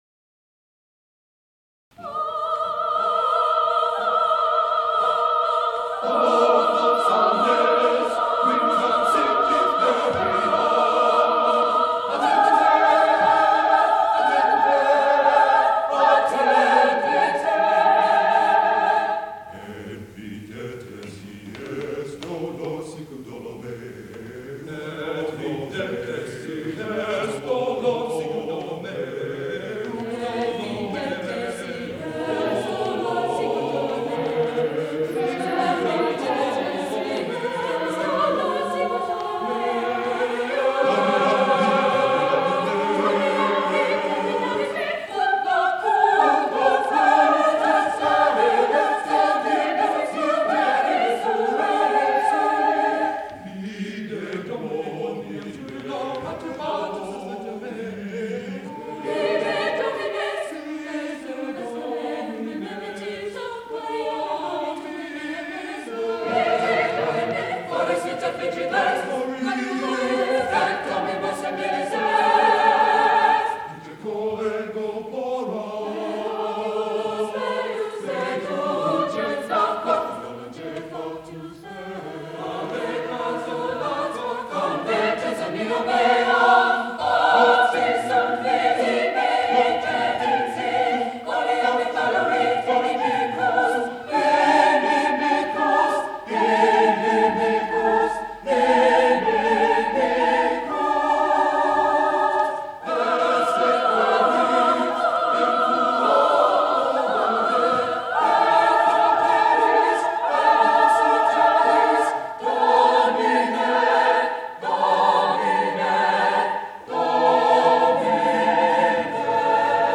Highland Park, MI, High School Concert Choirs, 1954-1969
CD for the 1969 Spring Concert